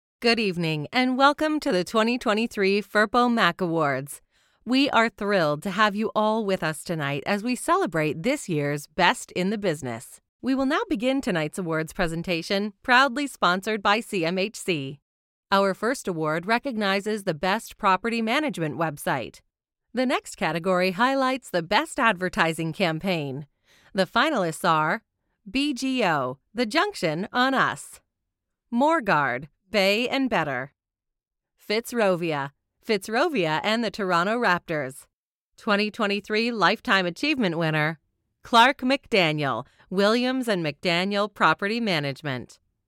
Inglês (canadense)
Esquentar
Conversacional
Relacionável